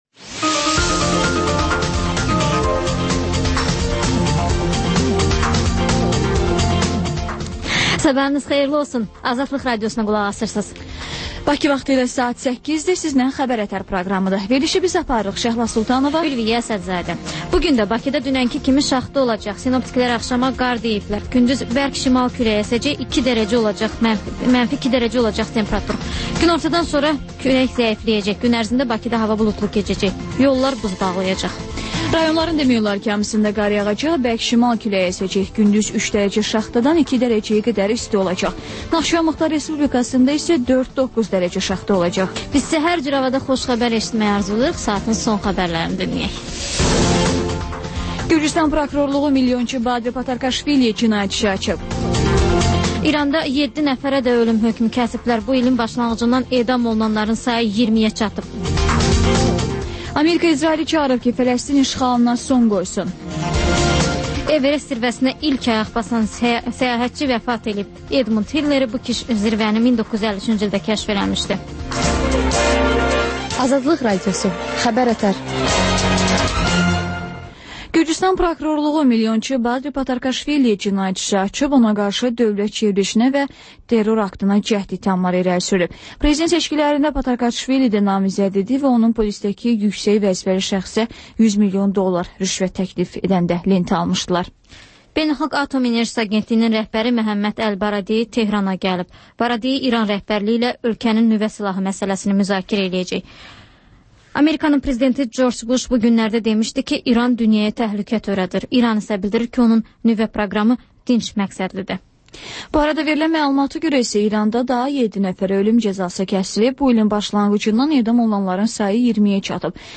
Xəbər-ətər: xəbərlər, müsahibələr və TANINMIŞLAR verilişi: Ölkənin tanınmış simalarıyla söhbət